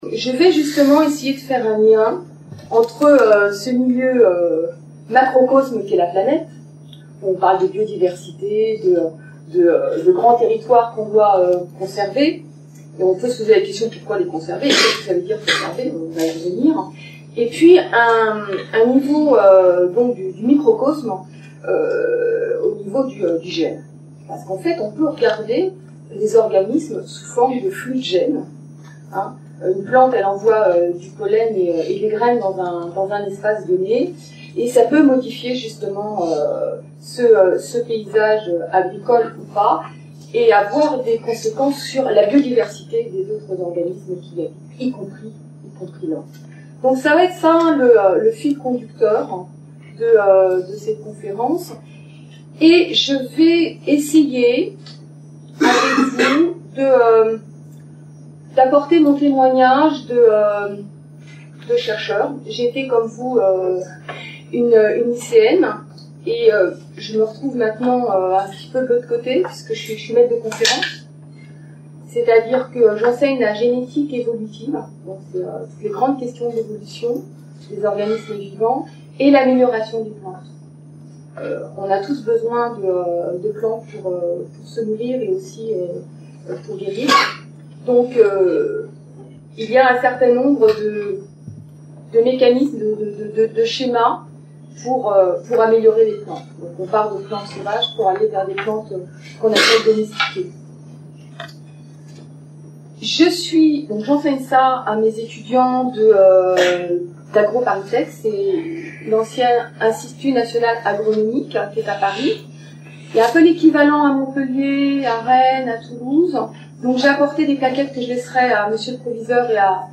Une conférence de l'UTLS au lycée Lycée Emile Duclaux (15 Aurillac)